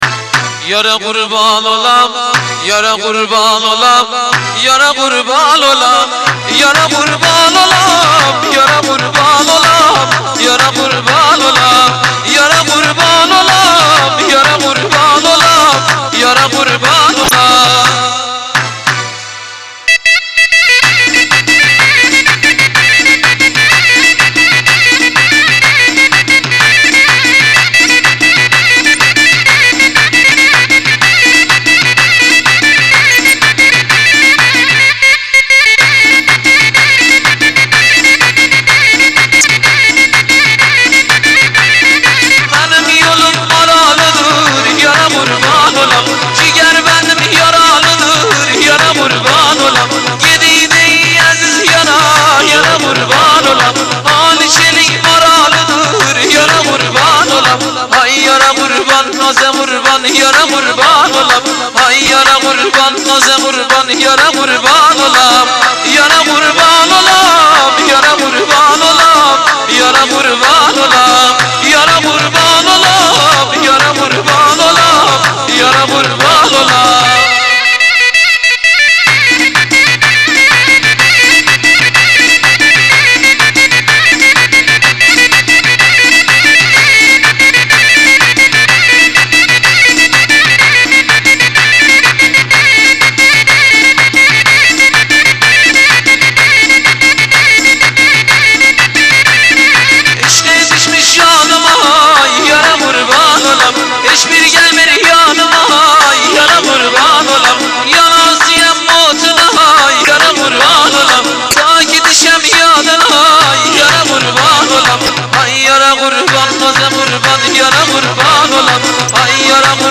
آهنگ ترکی شاد